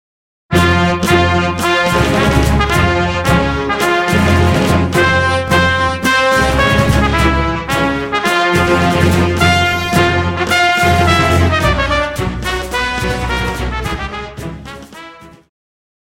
Pop
Trumpet
Band
Instrumental
World Music,Fusion
Only backing